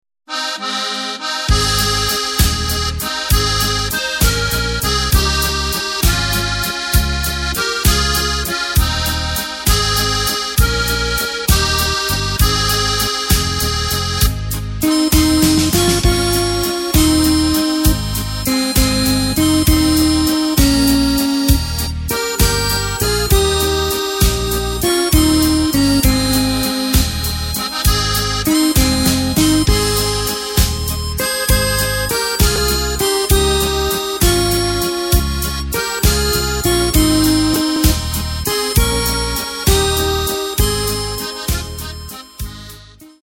Takt:          3/4
Tempo:         198.00
Tonart:            Ab
Walzer Tradition!